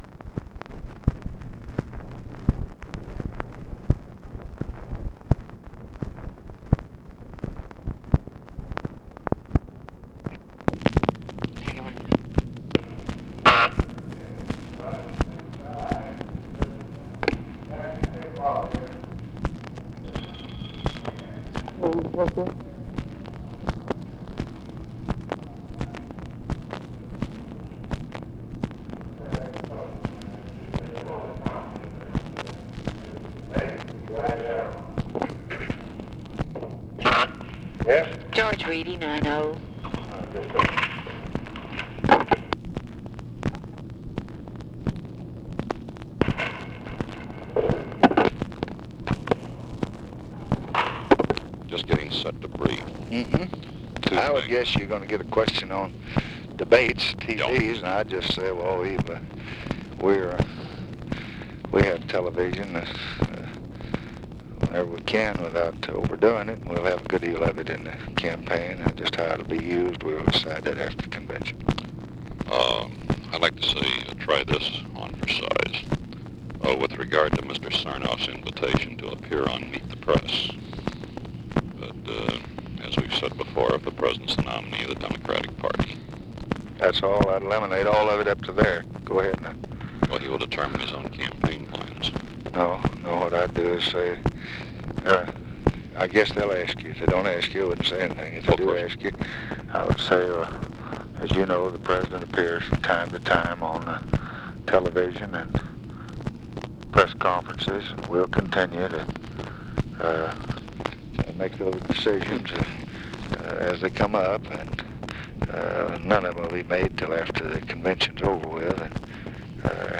Conversation with GEORGE REEDY and OFFICE CONVERSATION, August 20, 1964
Secret White House Tapes